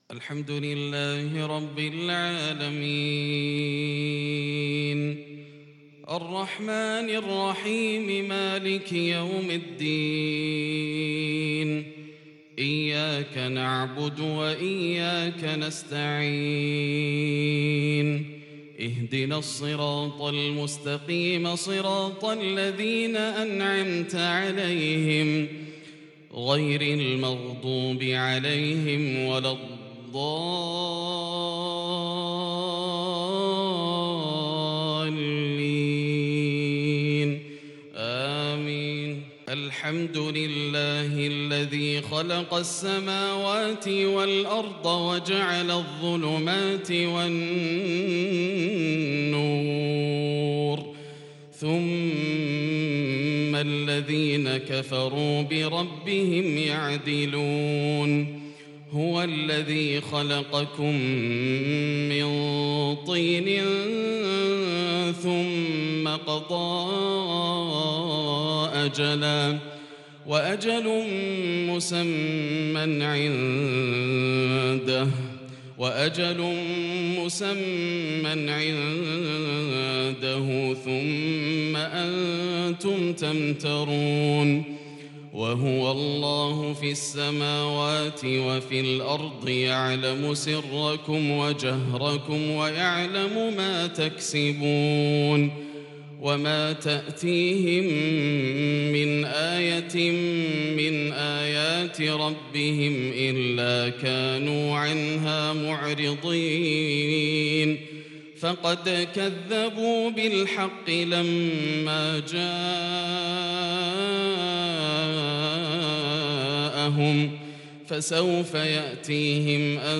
صلاة الفجر للشيخ ياسر الدوسري 24 ربيع الآخر 1442 هـ
تِلَاوَات الْحَرَمَيْن .